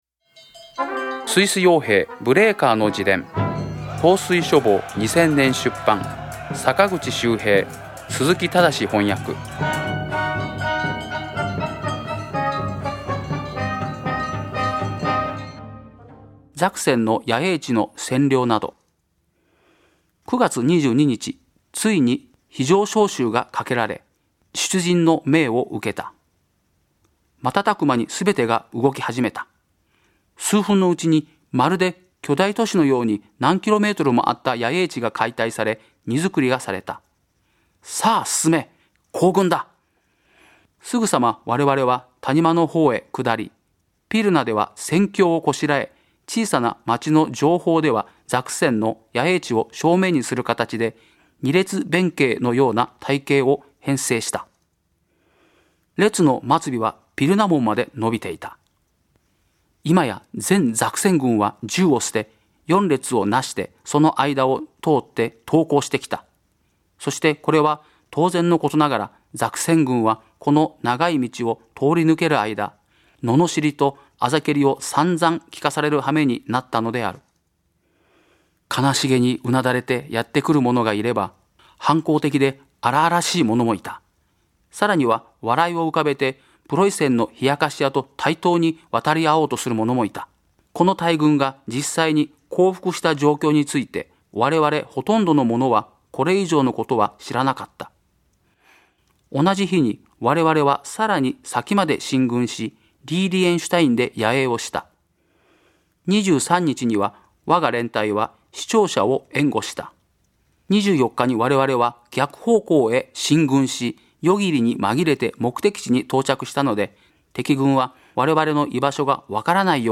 朗読『スイス傭兵ブレーカーの自伝』第58回